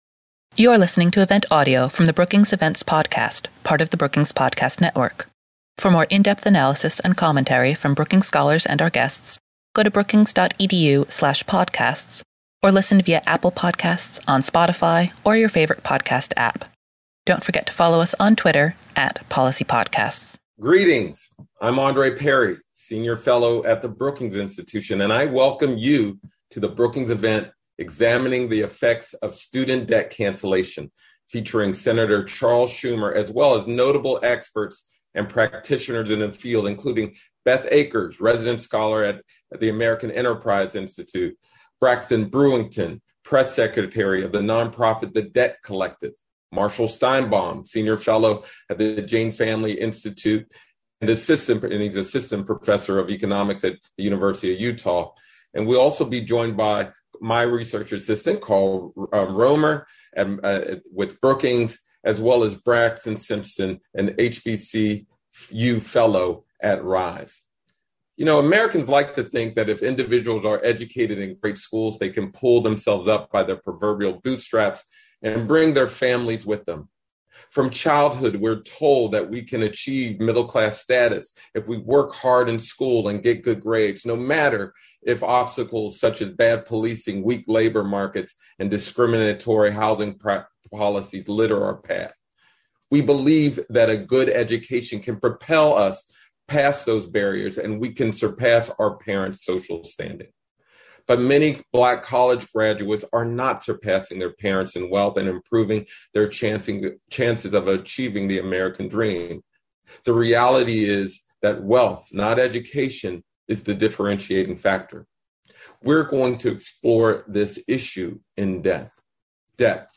On June 28, the Brookings Metropolitan Policy Program hosted a panel of experts who will discuss the effects of student debt cancellation.
Senate Majority Leader Chuck Schumer (D-N.Y.) provided keynote remarks focused on potential legislative and executive action, and panelists debated the merits of various student debt cancellation proposals.